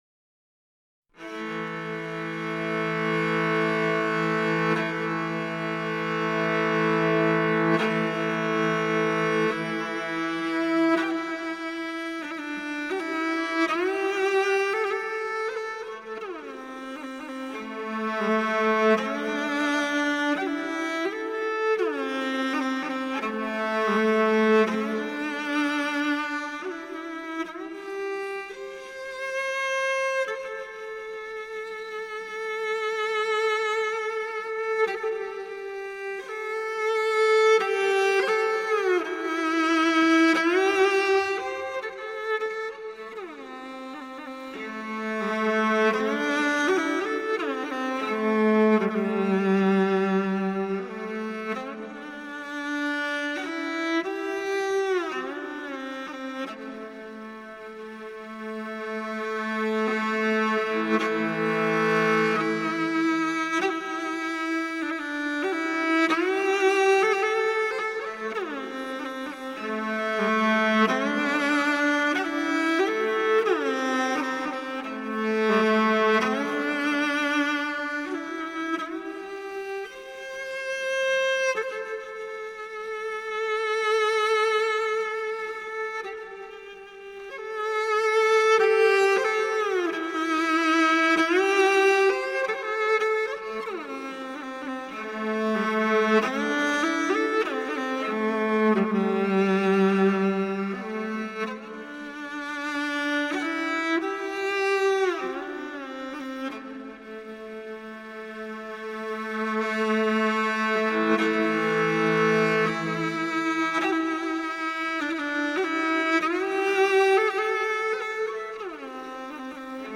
拉弦乐器2
马头琴